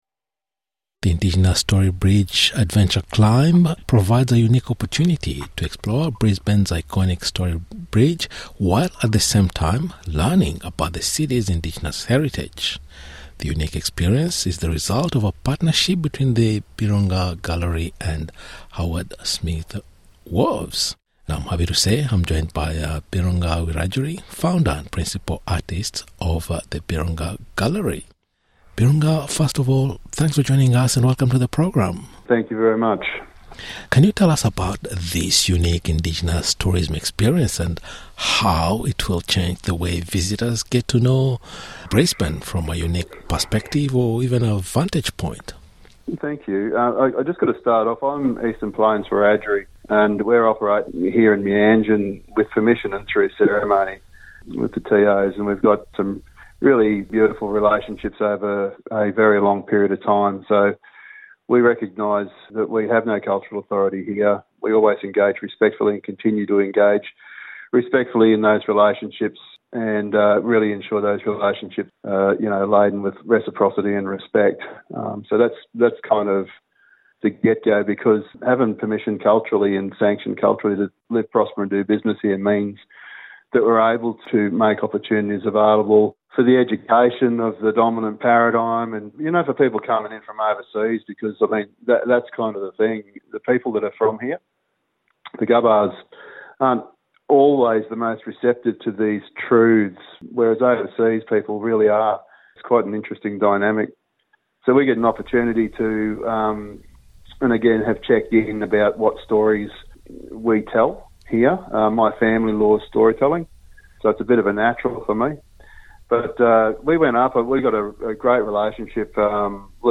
In a conversation with NITV Radio